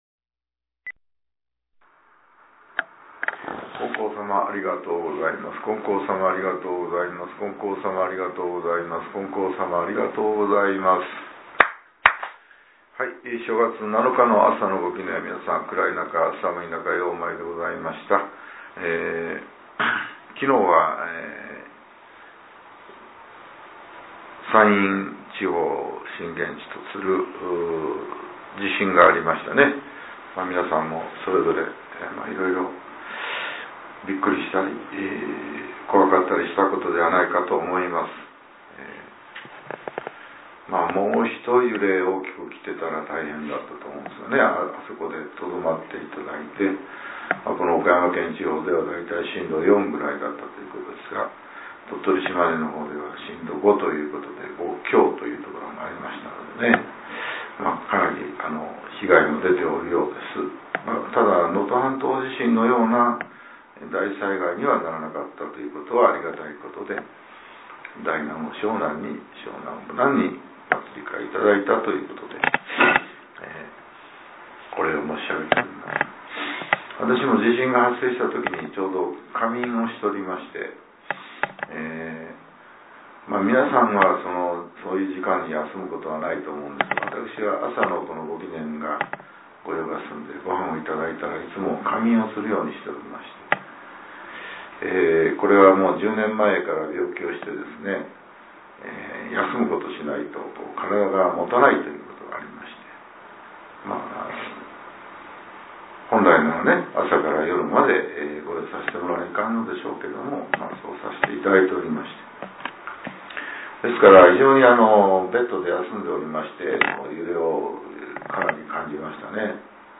令和８年１月７日（朝）のお話が、音声ブログとして更新させれています。